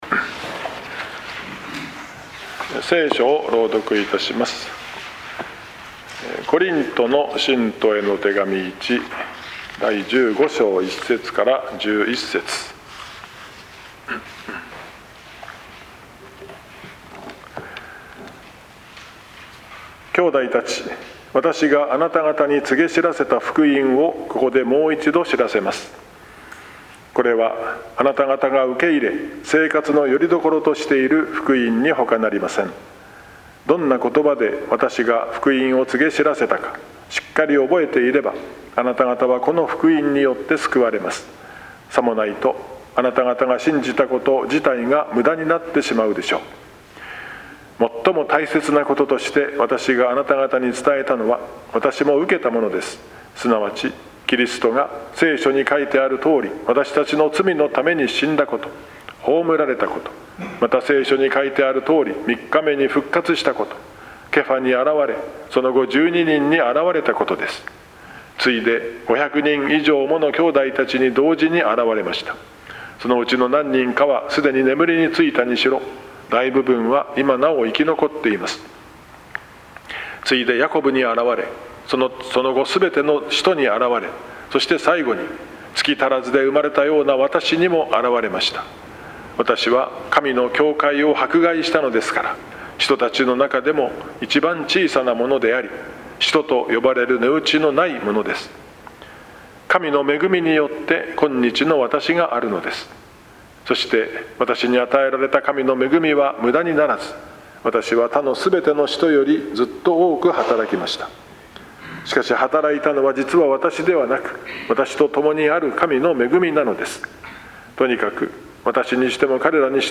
２０２４年４月の聖句についての奨励（４月３日 昼の聖書研究祈祷会） 「神の恵みによって今日のわたしがあるのです。」（１０節）